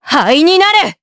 Seria-Vox_Skill3_jp.wav